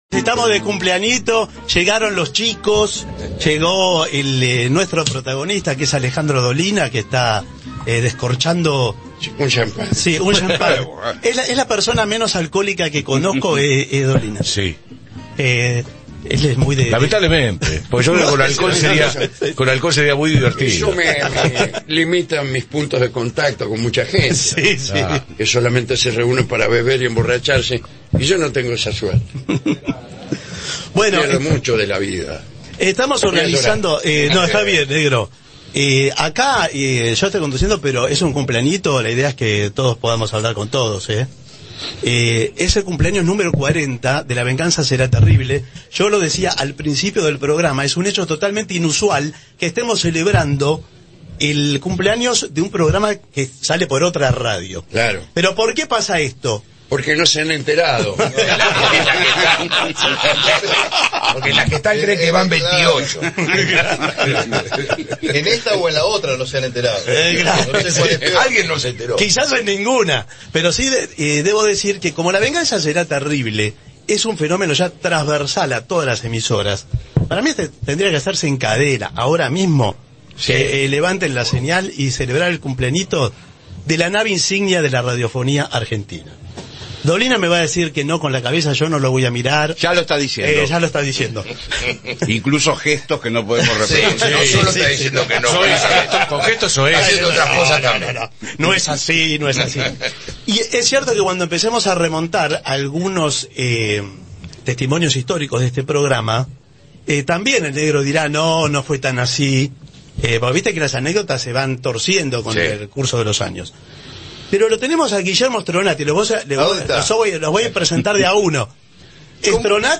Edición especial en Radio Splendid 990